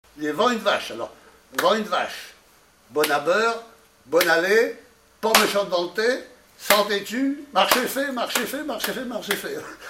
Genre conte
Pièce musicale inédite